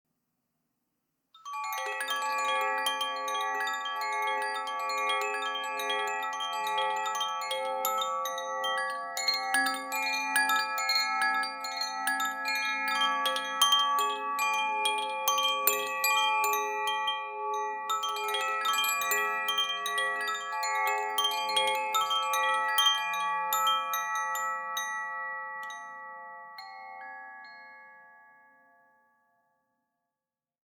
Meinl Sonic Energy Cosmic Bamboo Chime - 432 Hz/Sol (Tag) (CBCSOL)
Die Meinl Sonic Energy Cosmic Bamboo Chimes erzeugen weiche, nachklingende Töne, die Ihren Raum mit ruhigen Klängen erfüllen.
Im Inneren befinden sich ein Pendel und mehrere Metallstäbe, die auf bestimmte Tonhöhen gestimmt sind.